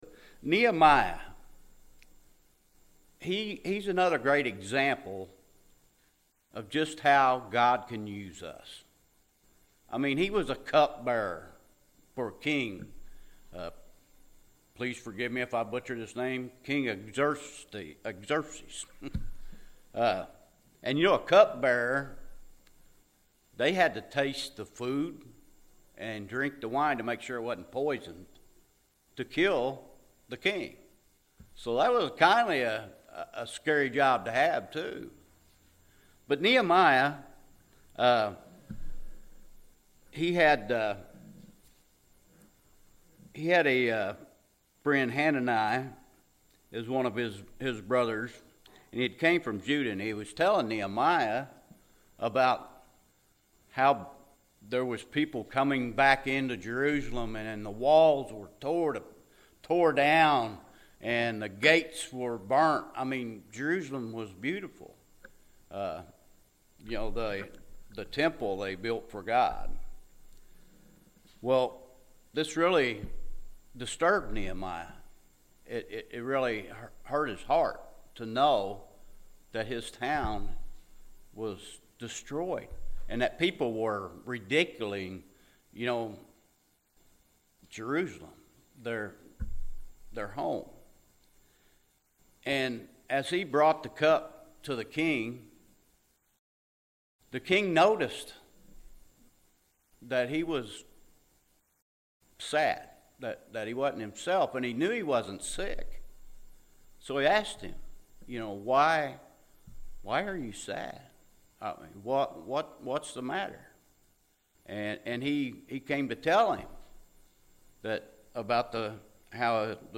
Nehemiah – A.M. Service